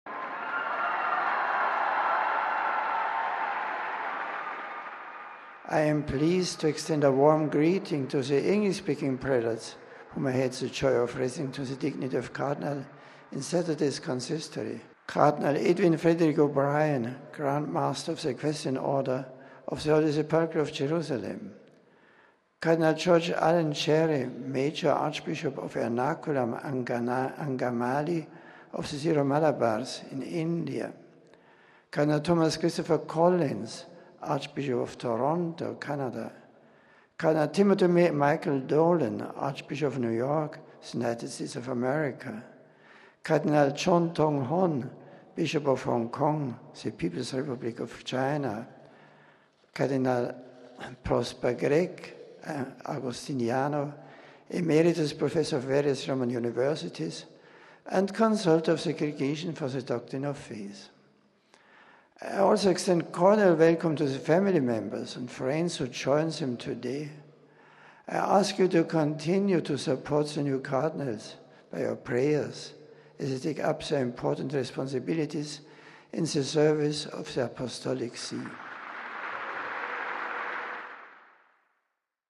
Pope Benedict held the traditional post-consistory audience today with newly created Cardinals and the pilgrims and family members accompanying them.
4 thousand people packed the Paul VI hall to hear the Holy Father’s greetings gathering in various delegations around the new Cardinals.